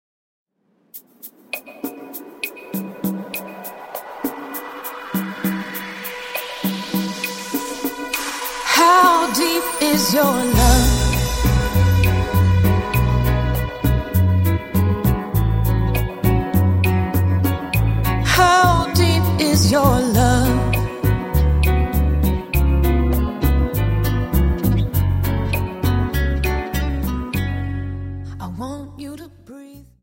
Dance: Rumba Song